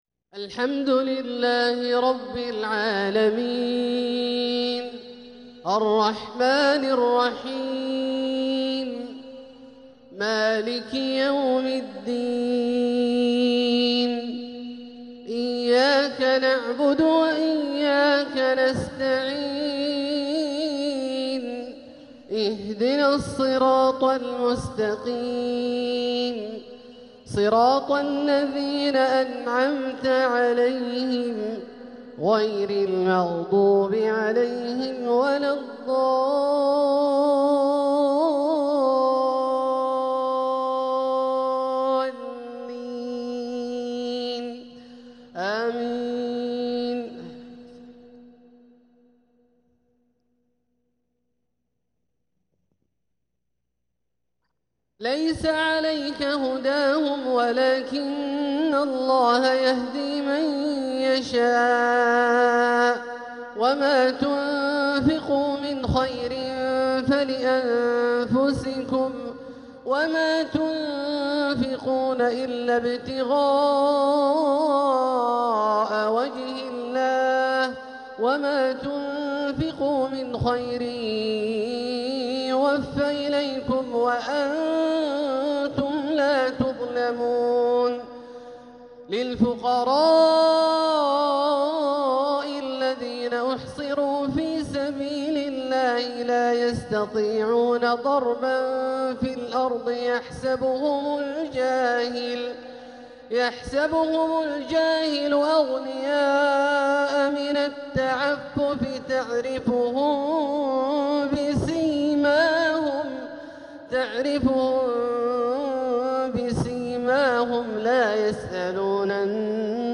تلاوة من سورة البقرة 272-281 | عشاء الأحد 20 ربيع الآخر1447هـ > ١٤٤٧هـ > الفروض - تلاوات عبدالله الجهني